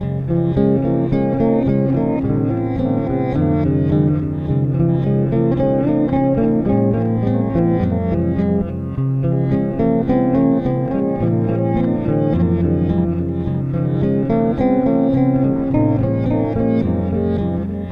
Loop (00:17)